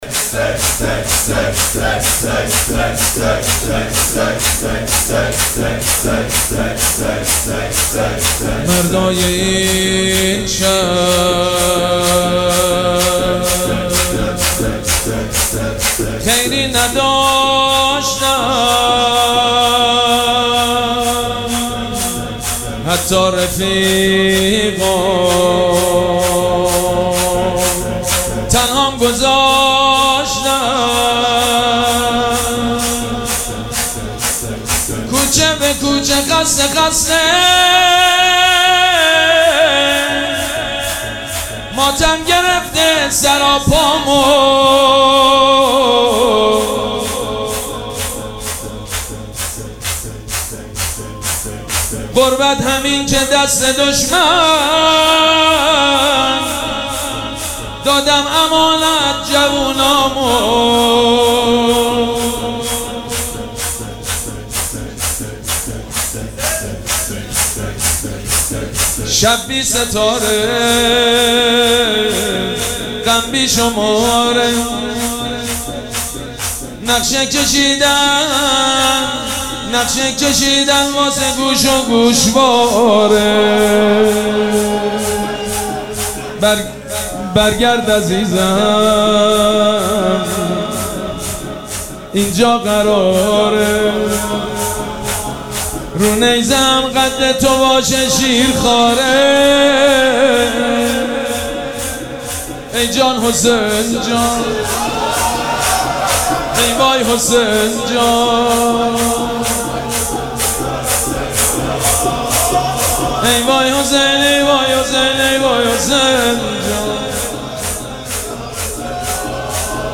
مراسم عزاداری شهادت امام محمد باقر و حضرت مسلم سلام‌الله‌علیهما
حسینیه ریحانه الحسین سلام الله علیها
شور